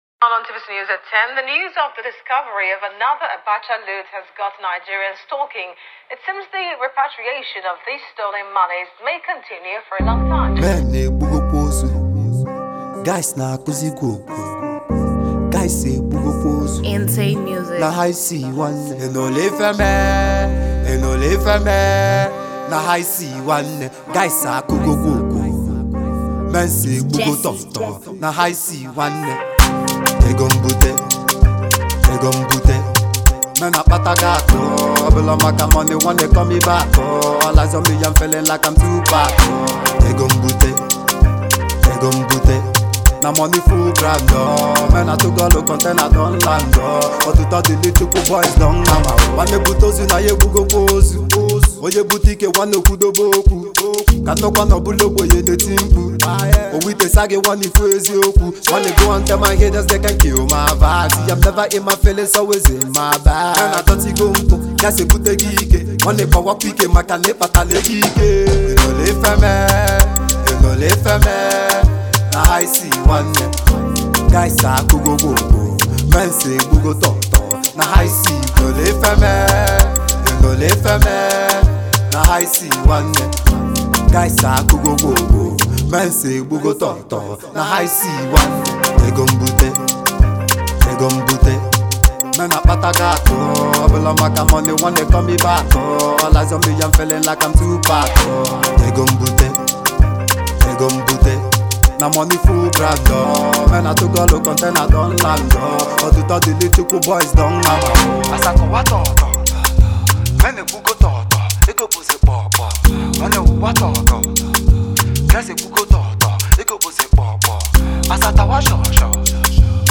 T.R.A.P-infused tracks